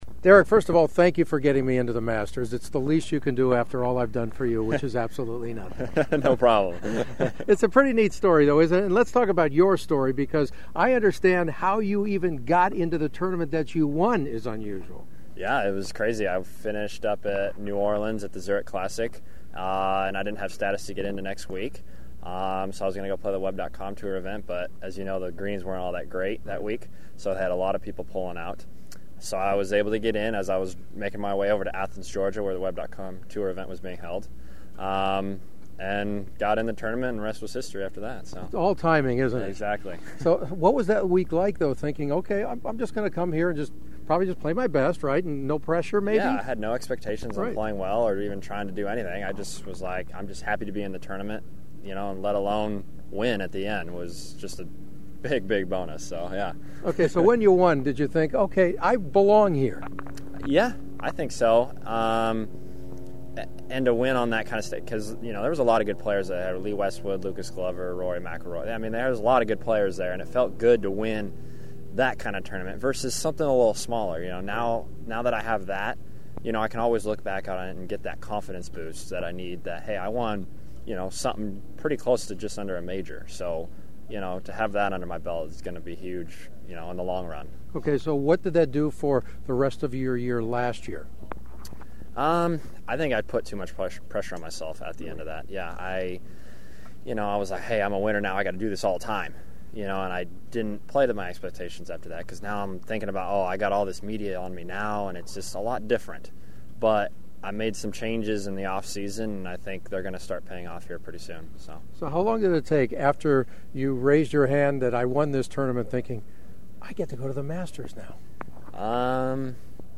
The following are some of my Masters preview interviews leading into Thursday’s first round.
at Riviera in February